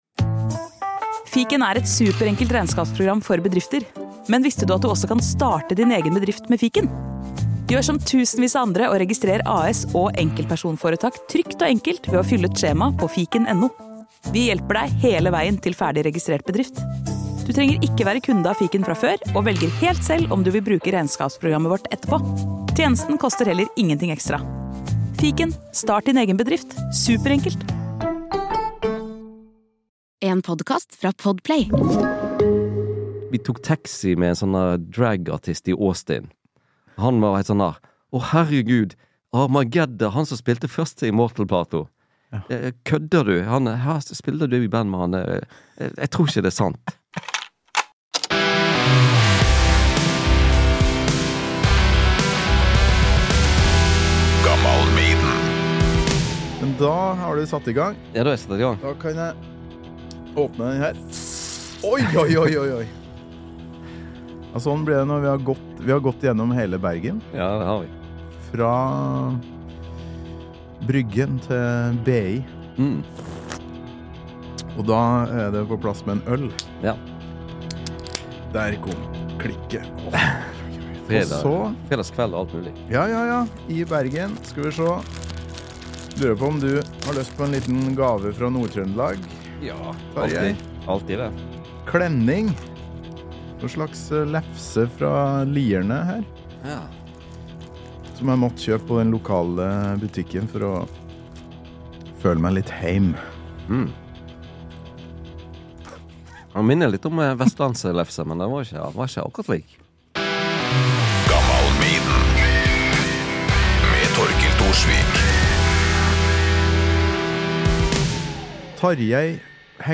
vandrer gjennom Bergen, møter musikkvenner og setter oss ned i et podkast-studio på BI. Her er det musikkoppvekst, kjendisprester som ikke liker Judas Priest, men er svake for Maiden, innblikk i "mysteriet Bergen" på 80-tallet og en god dose nerding.